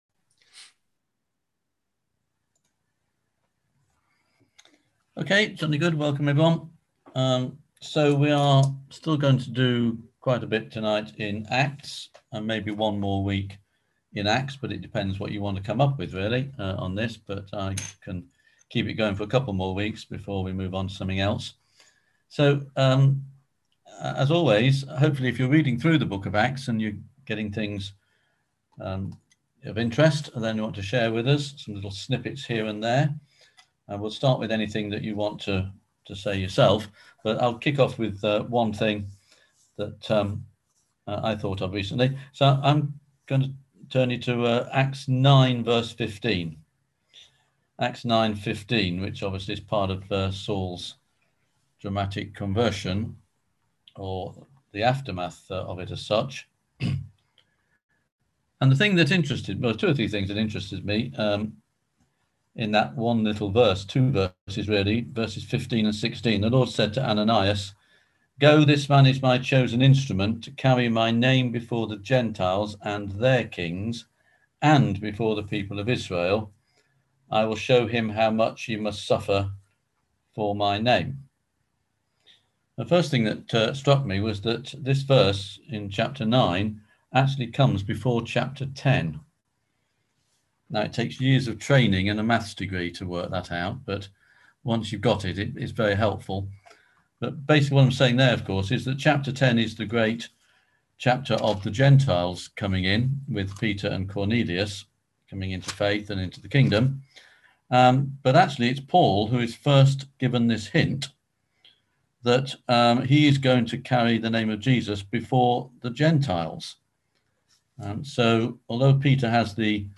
On October 14th at 7pm – 8:30pm on ZOOM